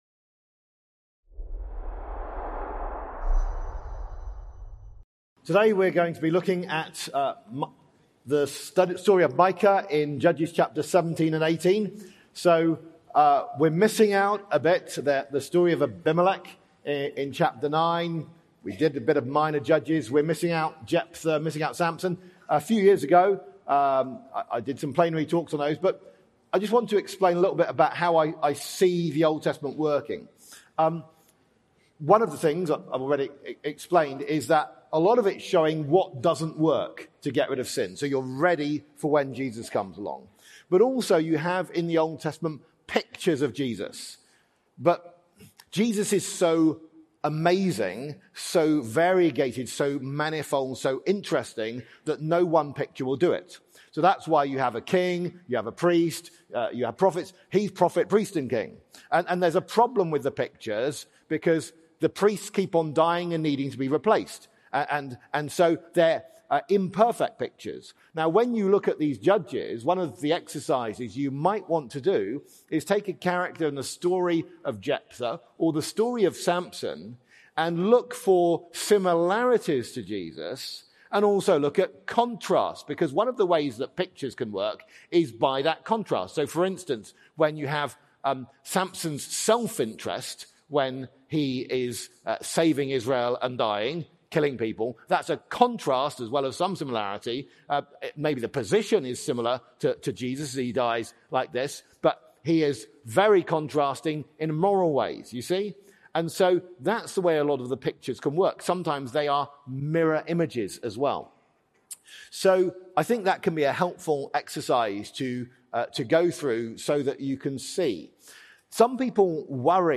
Event: ELF Plenary Session